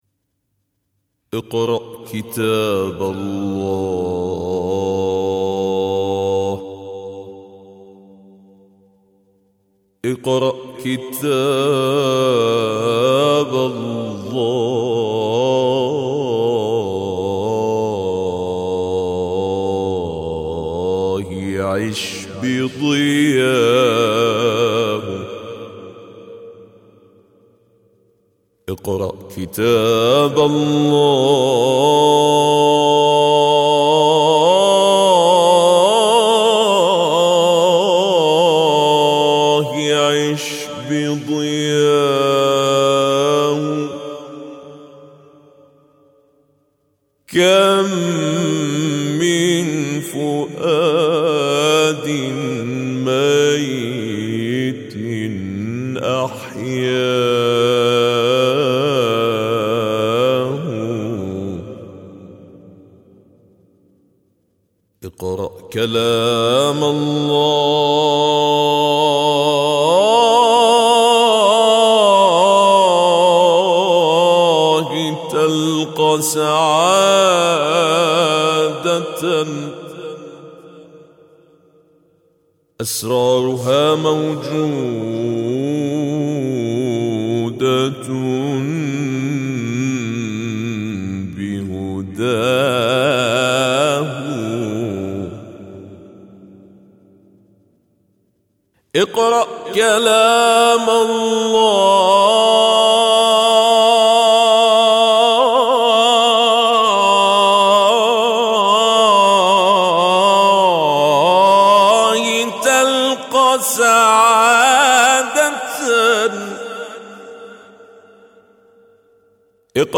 ابتهال: اقرأ كتاب الله...